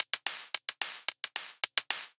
UHH_ElectroHatC_110-03.wav